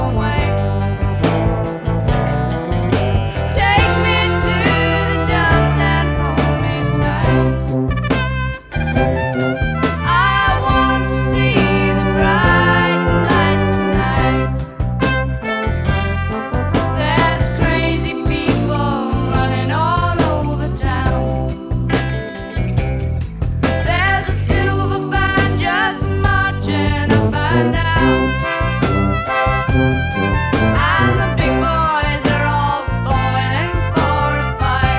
Жанр: Рок / Фолк-рок